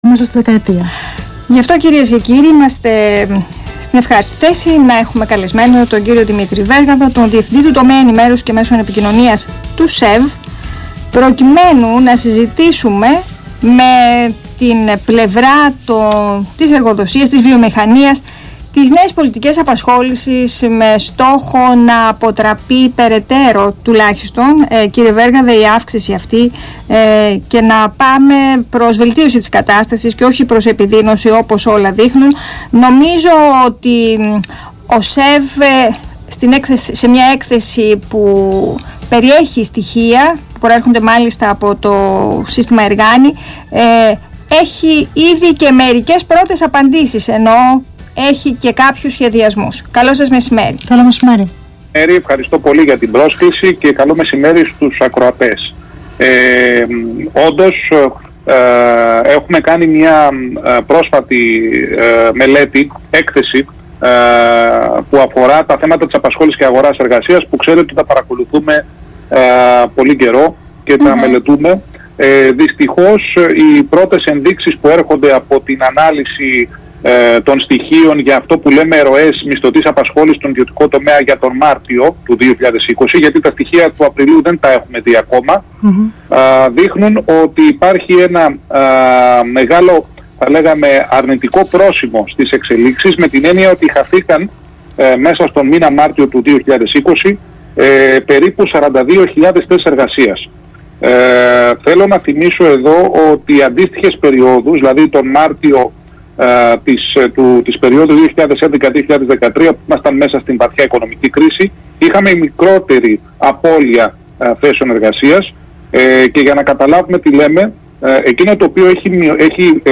Συνέντευξη
στον Ρ/Σ ΑΘΗΝΑ 9.84